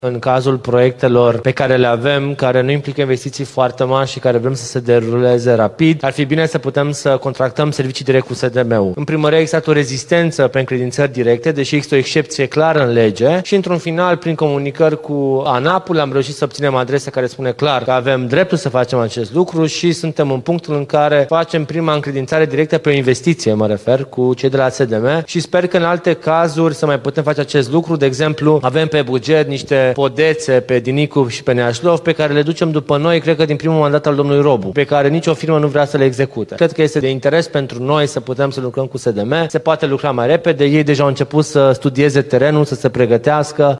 Viceprimarul Ruben Lațcău spune că primul proiect de care se va ocupa SDM vizează un tronson din Calea Buziașului.